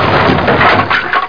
CRASH.mp3